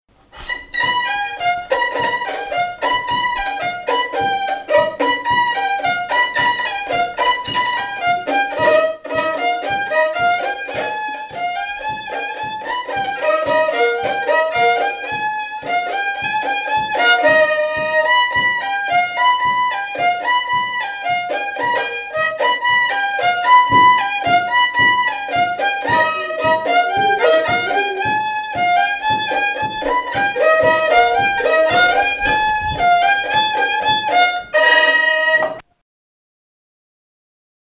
Mémoires et Patrimoines vivants - RaddO est une base de données d'archives iconographiques et sonores.
danse-jeu : tabouret, chaise, tréteau
Pièce musicale inédite